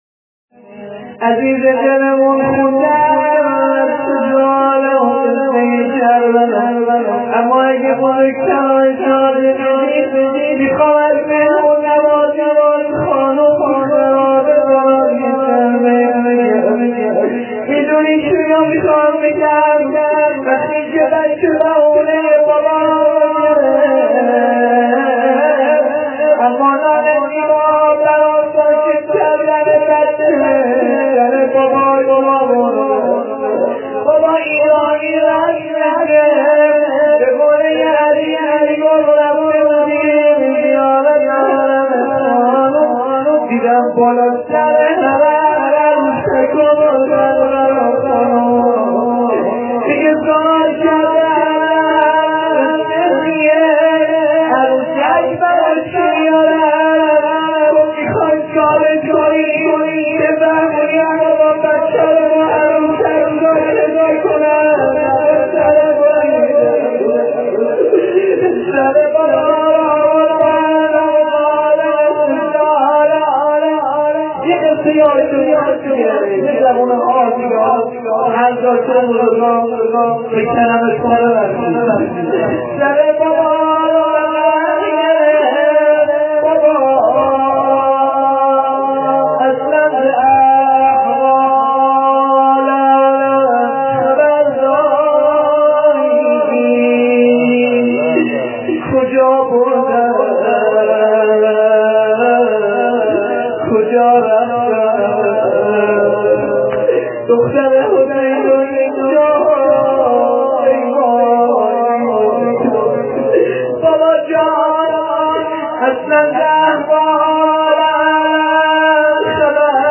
خیمه گاه - هیئت متوسلین به قمر بنی هاشم - روضه حضرت رقیه شب سوم محرم سال ۹۶ شمسی